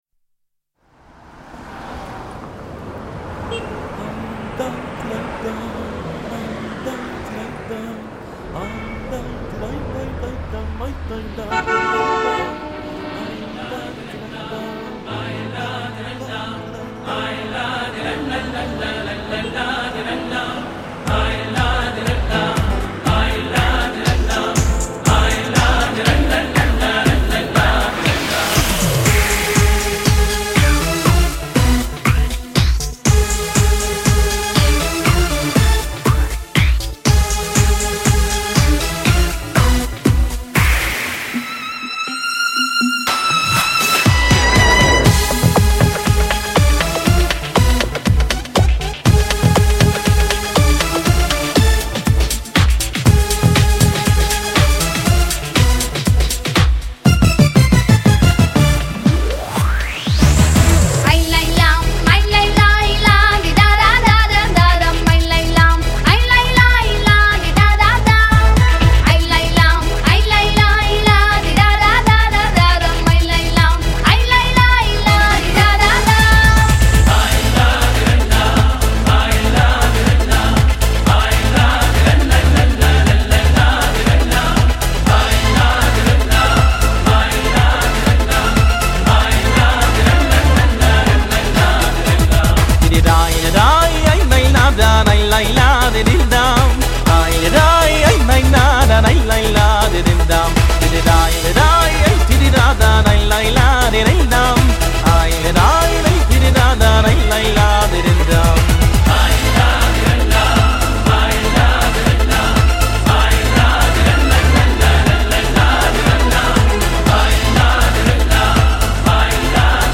ניגון חב"די אבוד
ניגון חב"די עתיק שטרם ראה אור.
אבל חלק ב' הולך לכיוון אחר.
עיבוד נהדר ומלא מרץ וכיף!
למישהו יש הסבר מה הרעשים הנלווים צפצופים וכדו', מה יש?
תודה על השיר המקפיץ שיעשה את הבין הזמנים